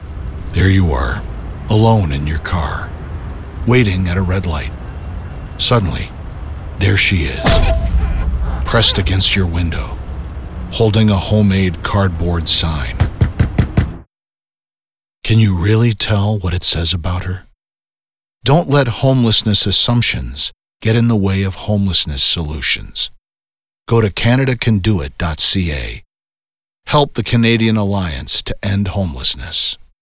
The new PSA “Sign” is a startling message about Canada’s devastating homelessness crisis.